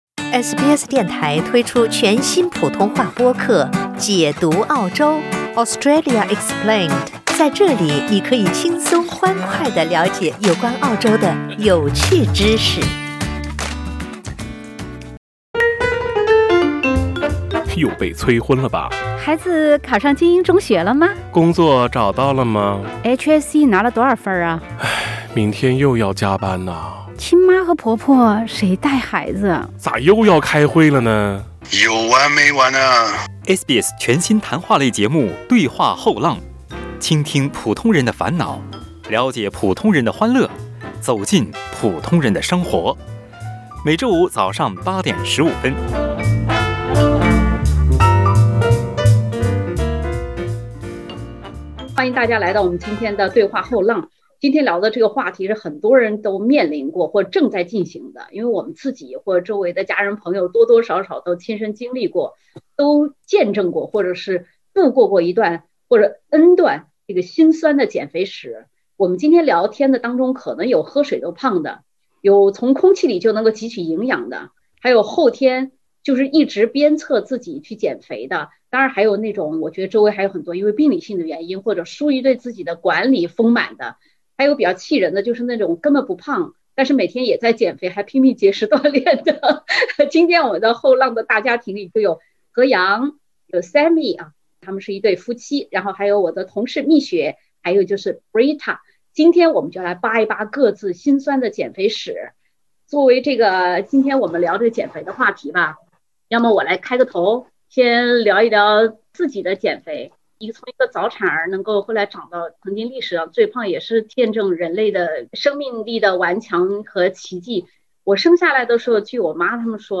欢迎收听澳大利亚最亲民的中文聊天类节目-《对话后浪》。